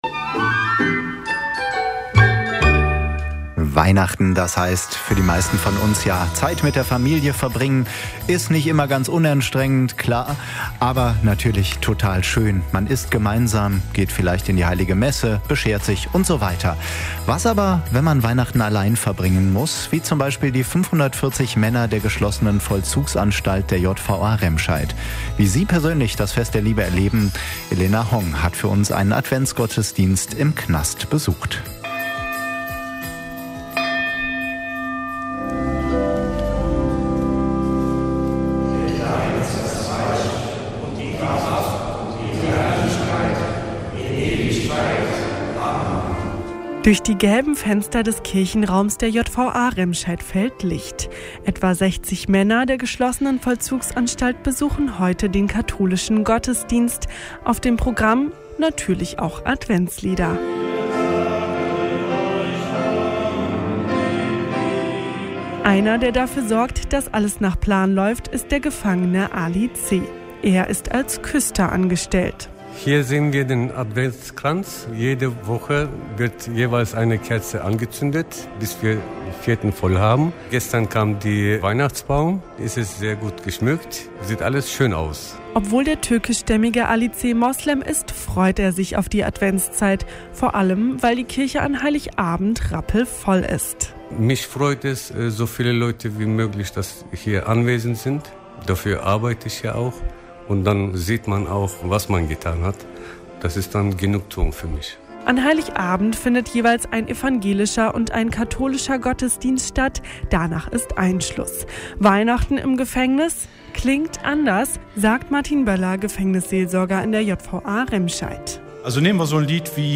(Beitrag vom 25.12.2019) Veröffentlicht: Mittwoch, 25.12.2019 07:01 Anzeige play_circle play_circle Himmel & Erde Adventsgottesdienst in der JVA Remscheid download play_circle Abspielen download Anzeige